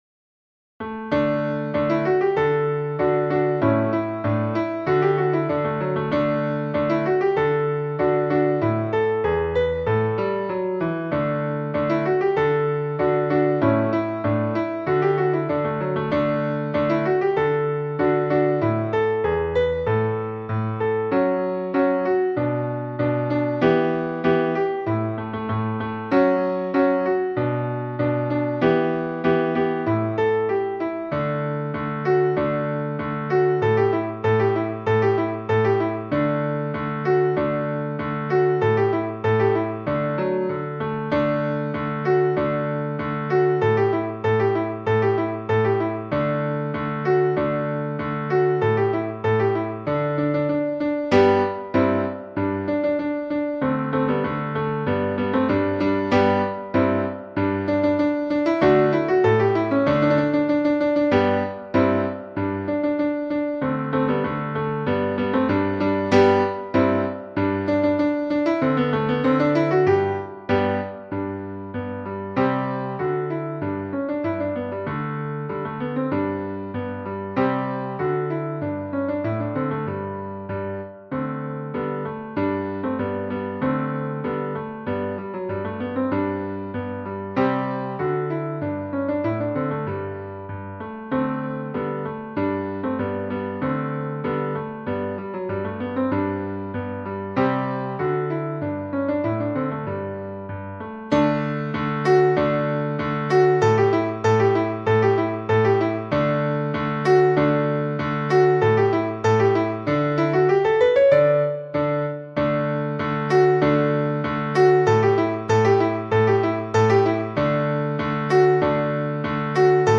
In effetti, la sua musica è scorrevole e piacevolissima e ve la consiglio per esercitazioni senza soverchie difficoltà.